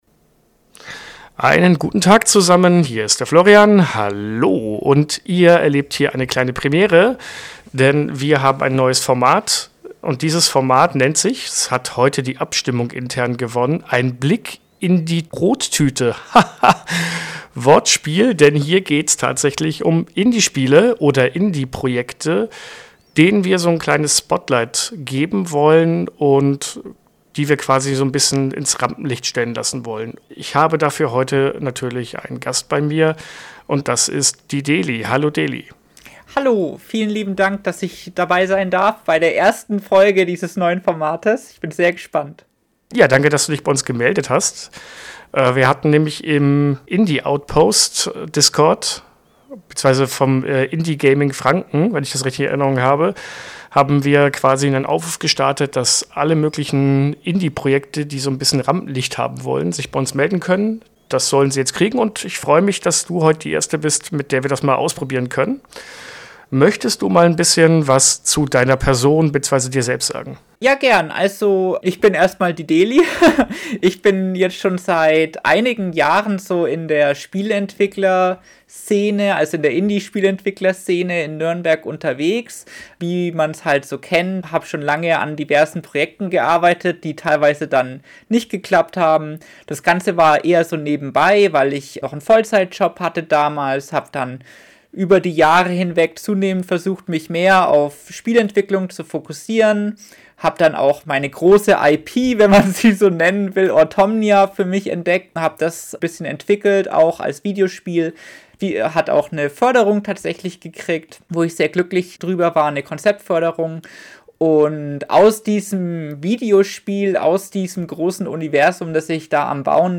* Ich nehme kränkelnd Podcast auf ... nur falls ihr euch wundert wieso ich klinge wie ich klinge.